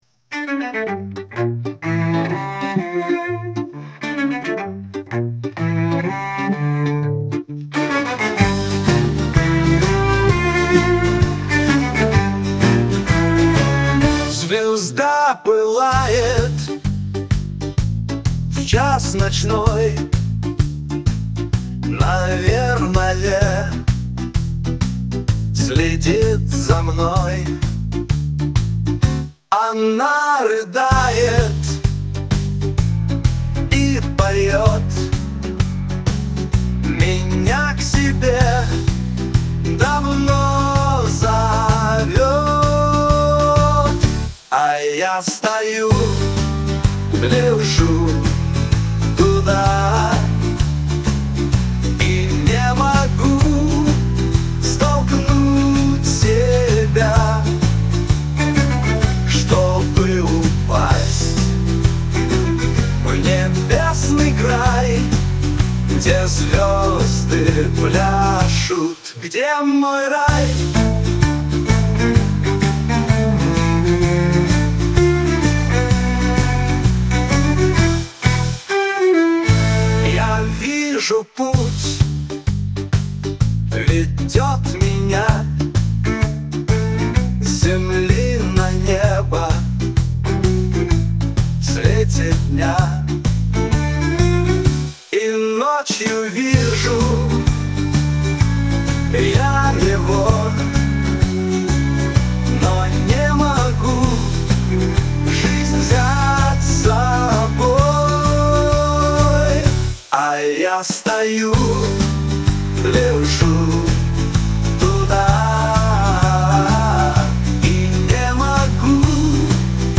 reverb vocal, acoustic guitar, cello, drums